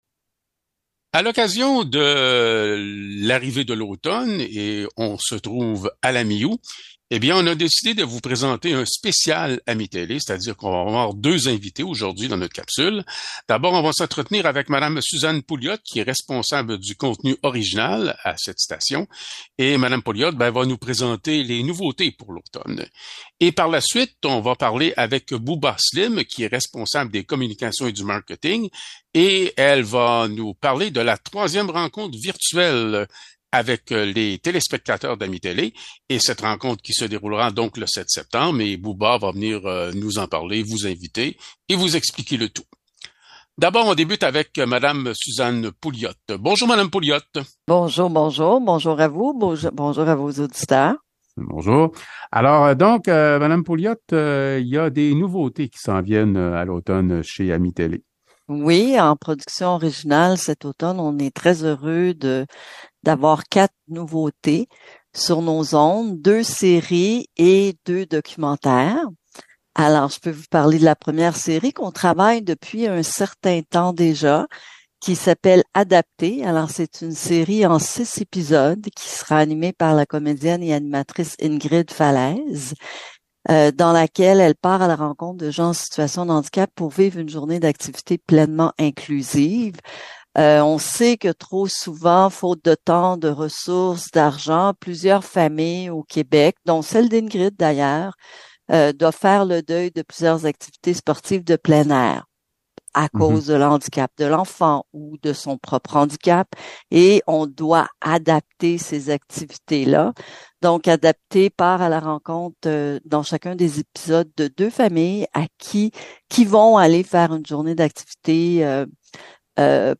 Nous vous proposons en prévision de la rentrée un spécial AMI-Télé avec deux invitées.